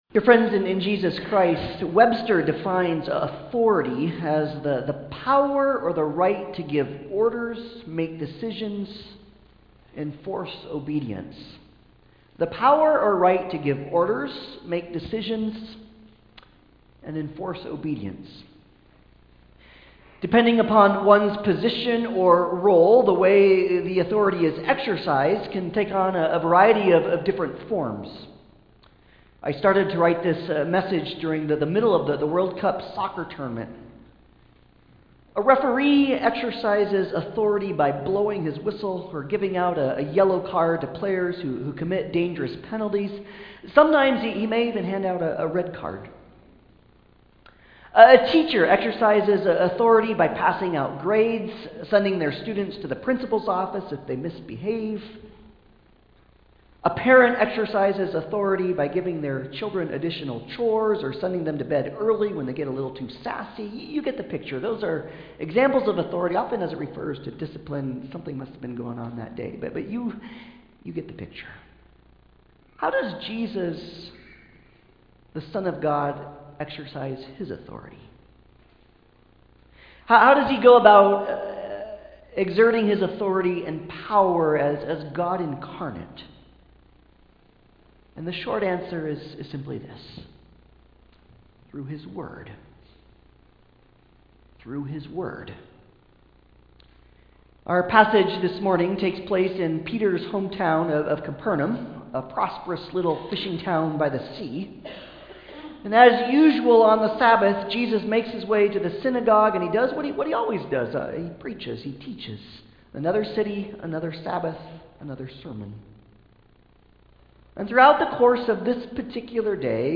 Passage: Luke 4:31-44 Service Type: Sunday Service